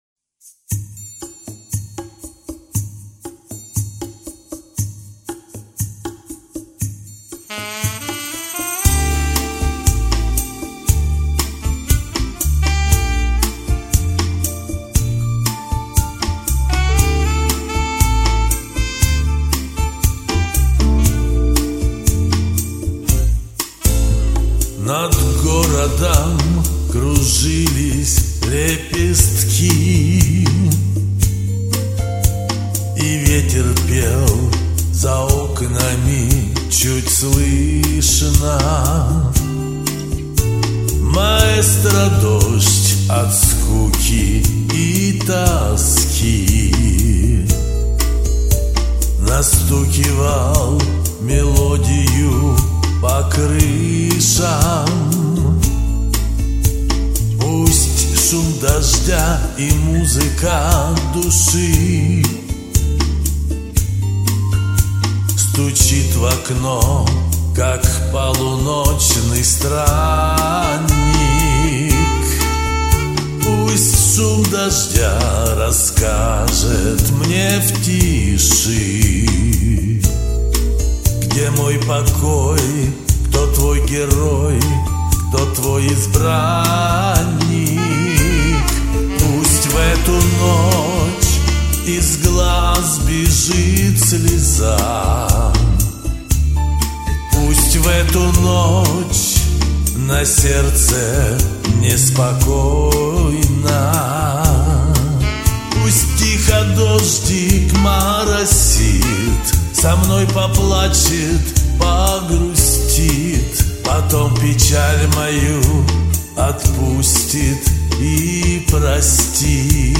более мягкие и "бархатистые" низы